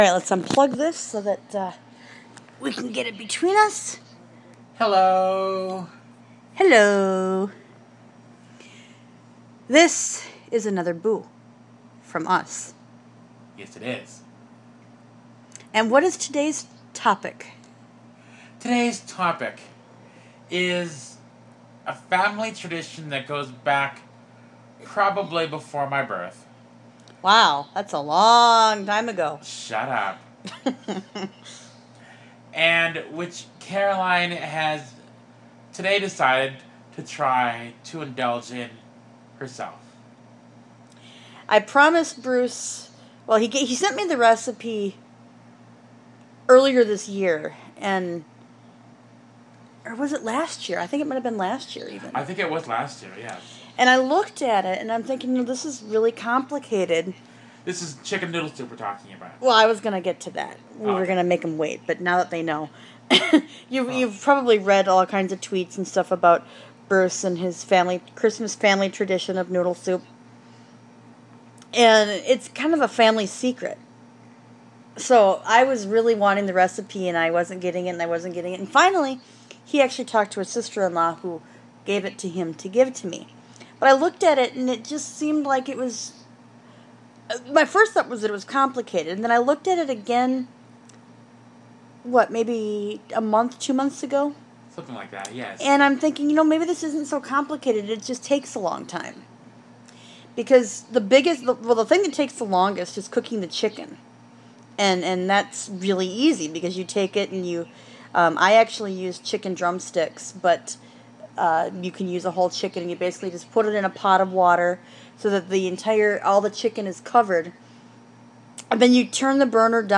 Soupberb Conversation